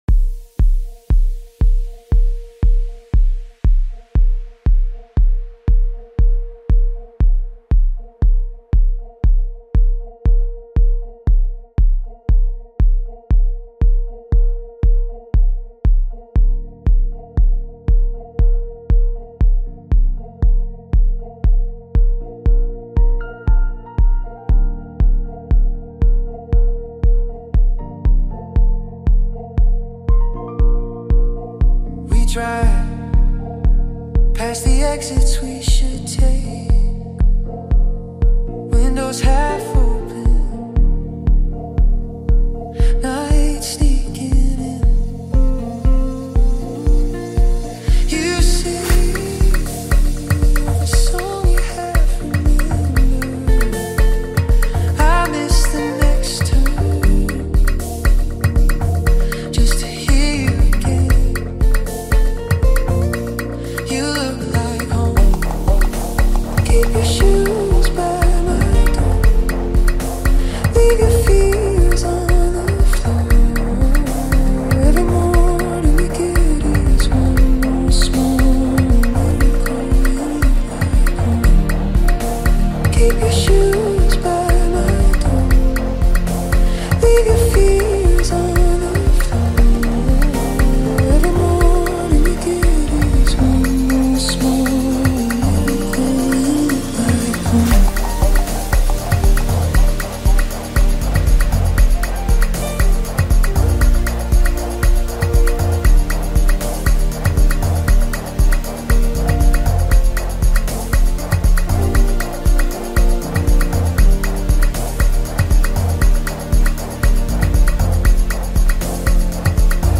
catchy melodies